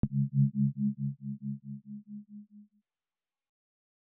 tone2.L.wav